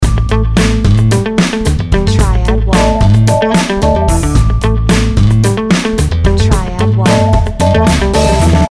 ROCK-style BGM played by electric guitar.